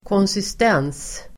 Ladda ner uttalet
konsistens substantiv, consistency Uttal: [kånsist'en:s] Böjningar: konsistensen Definition: grad av fasthet Exempel: mjuk konsistens (soft consistency), fast konsistens (solid consistency) consistency substantiv, konsistens